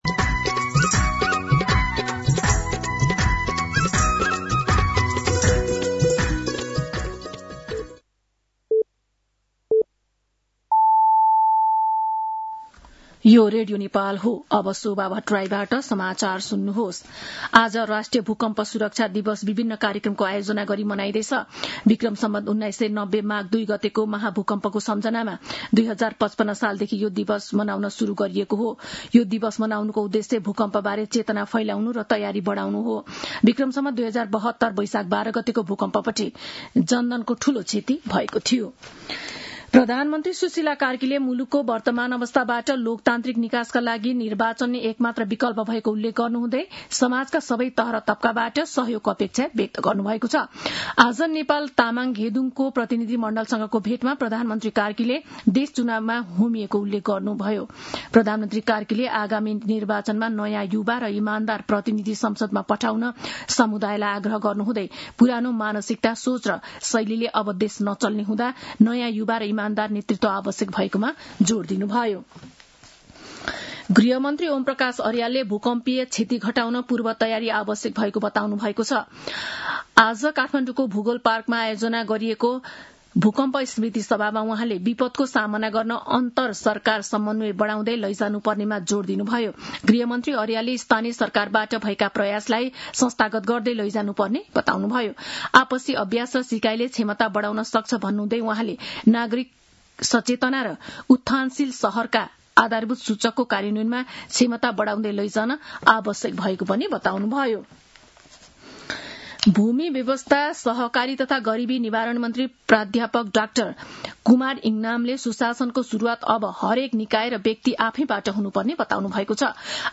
दिउँसो ४ बजेको नेपाली समाचार : २ माघ , २०८२